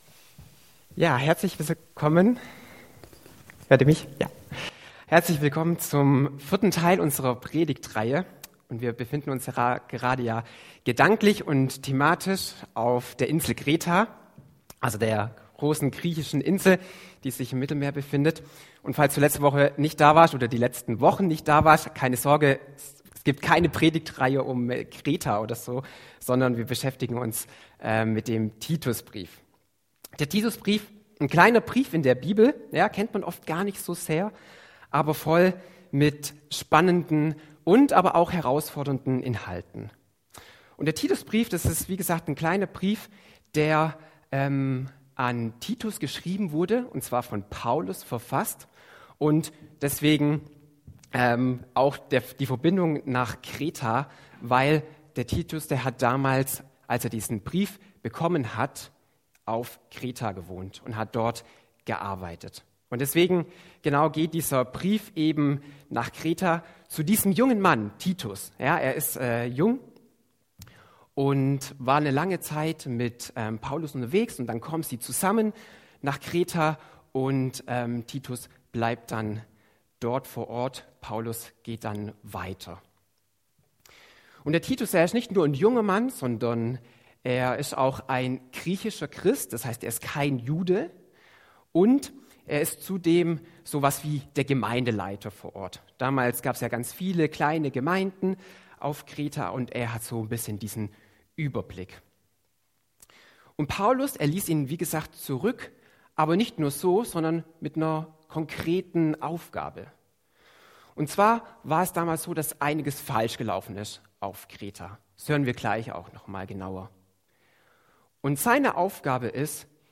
Predigt 25.08.2024 - SV Langenau